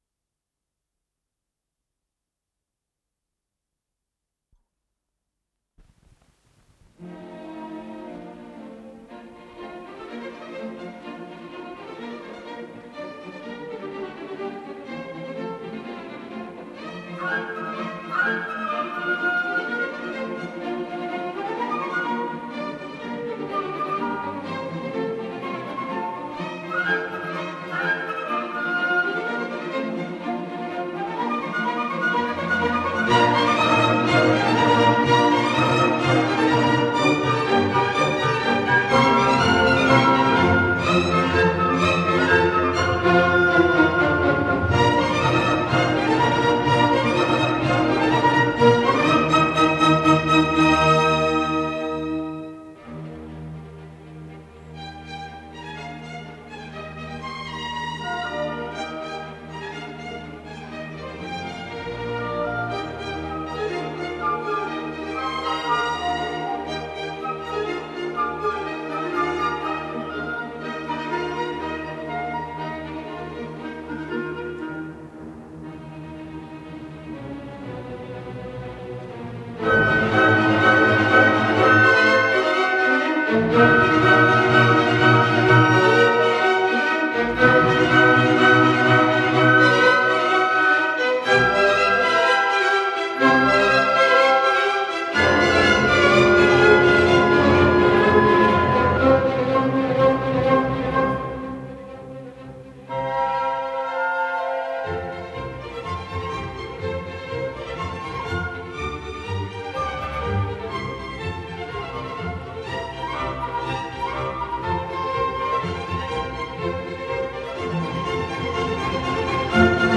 с профессиональной магнитной ленты
Престо виваче
ИсполнителиГосударственный академический симфонический оркестр СССР
Дирижёр - Джансуг Кахидзе